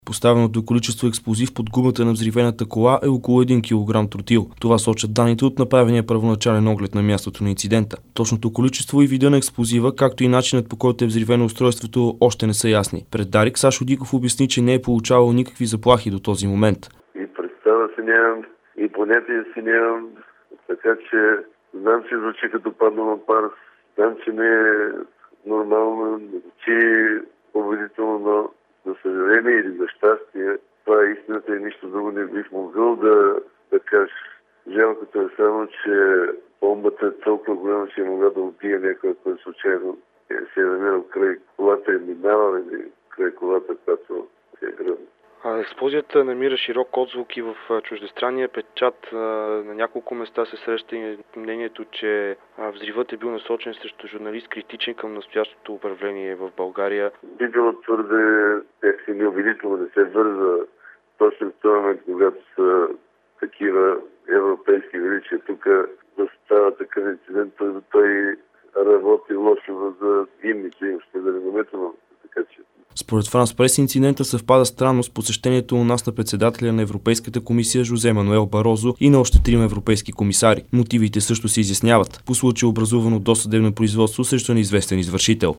Репортаж